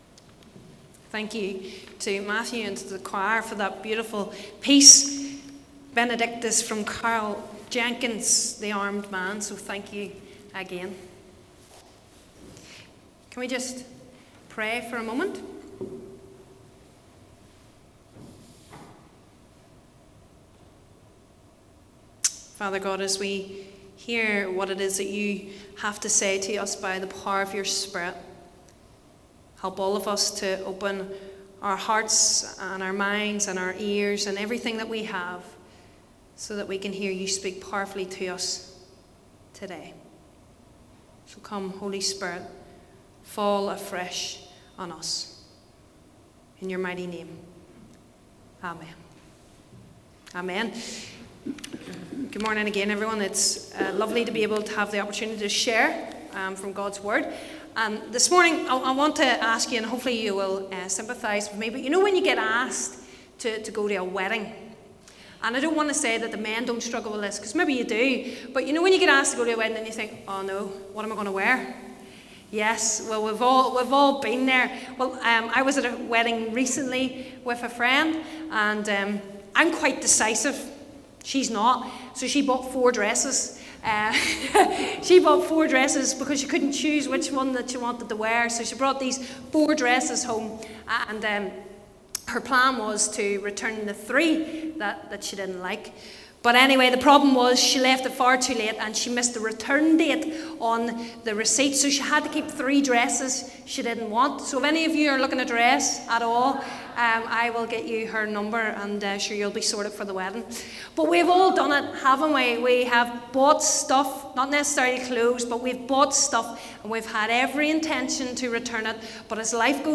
In this sermon we thought about the ways we are called to return to the lord.
8th-March_Sermon.mp3